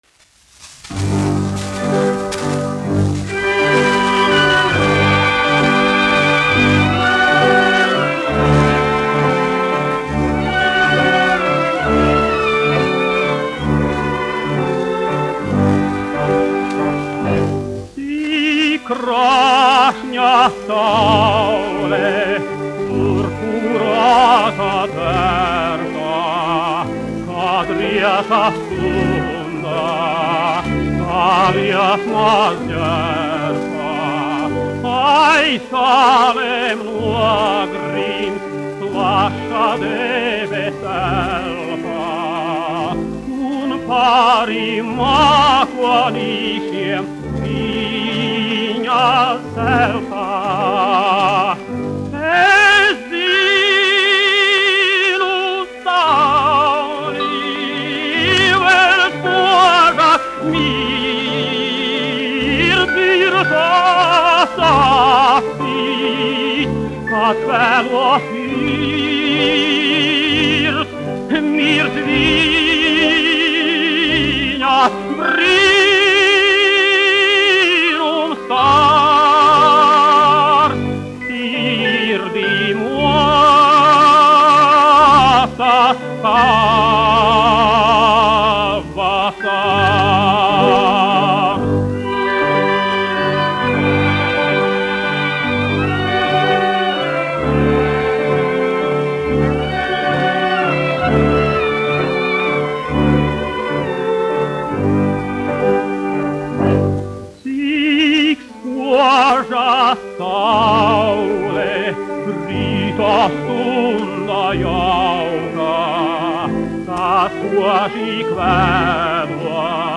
1 skpl. : analogs, 78 apgr/min, mono ; 25 cm
Populārā mūzika -- Itālija
Skaņuplate
Latvijas vēsturiskie šellaka skaņuplašu ieraksti (Kolekcija)